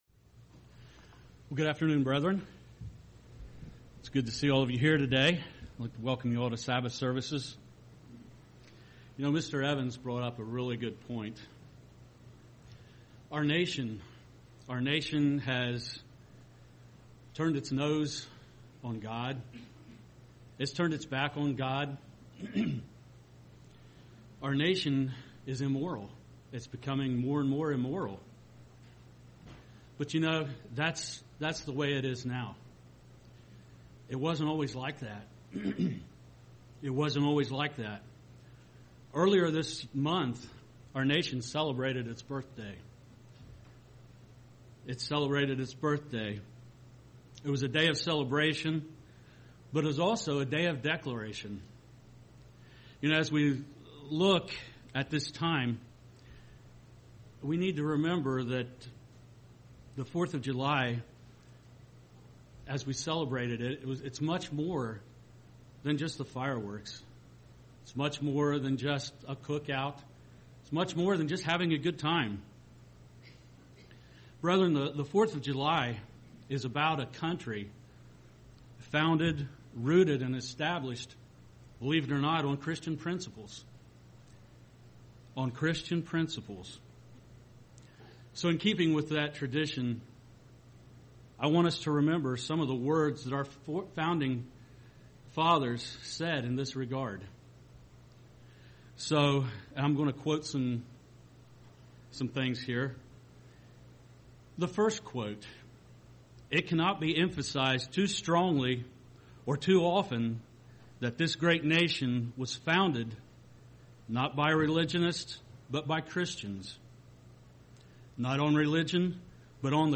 UCG Sermon Studying the bible?